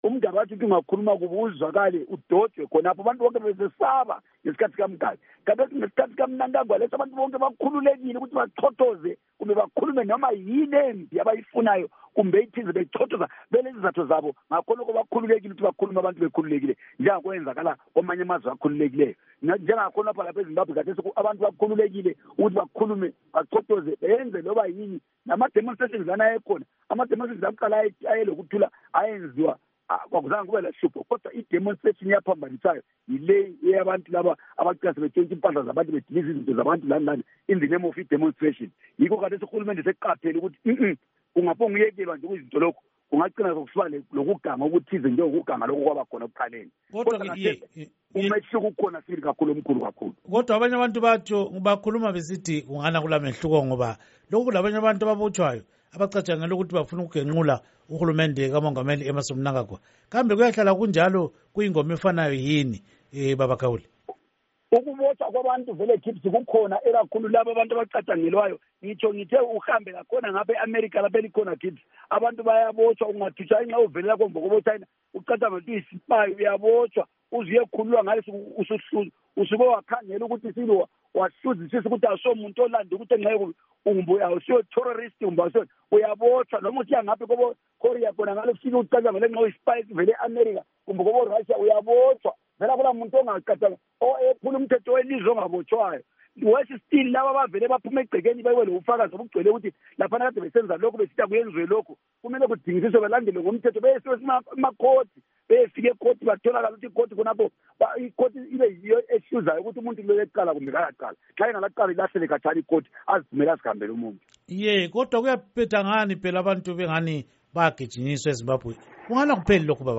Ingxoxo Esiyenze LoMnu. Believe Gaule